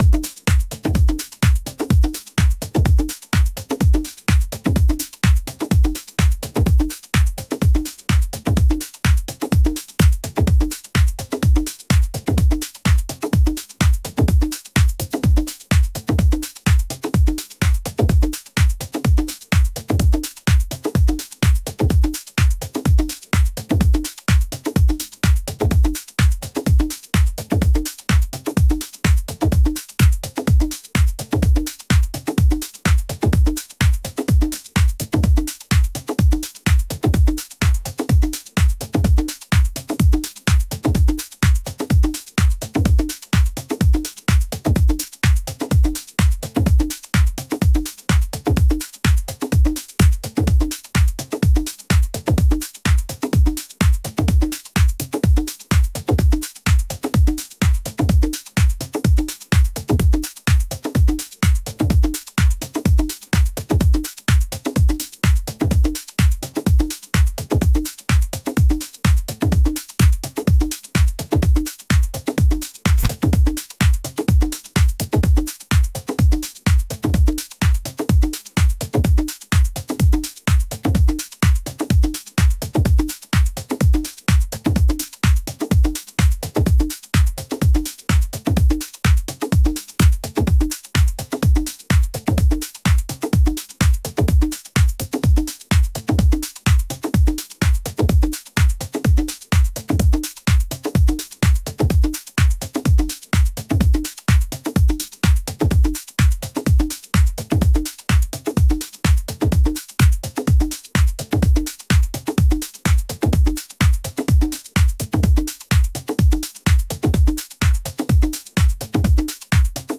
electronic | groovy